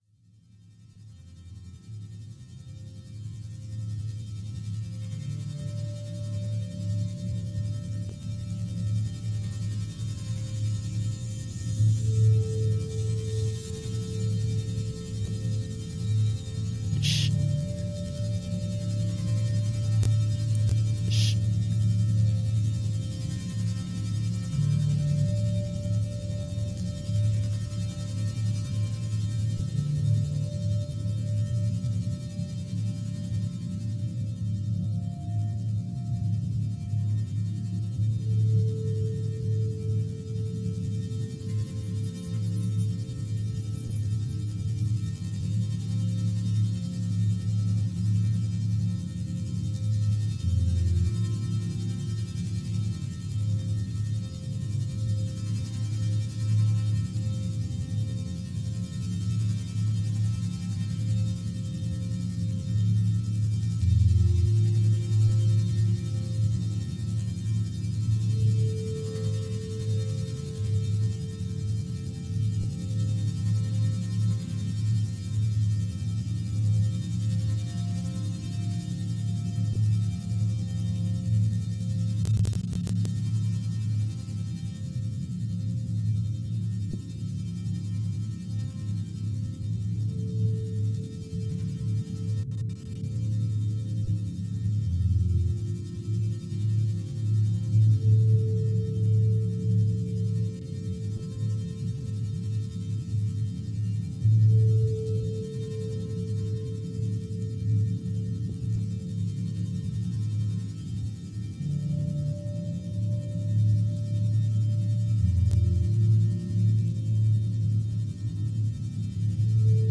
"The Conduction Series" is a collaborative live radio broadcast produced by sound and transmission artists across the Americas on Wave Farm’s WGXC 90.7-FM Radio for Open Ears in New York’s Upper Hudson Valley.